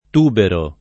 tubero [ t 2 bero ] s. m.